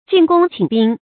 禁攻寝兵 jìn gōng qǐn bīng
禁攻寝兵发音
成语注音 ㄐㄧㄣˋ ㄍㄨㄙ ㄑㄧㄣˇ ㄅㄧㄥ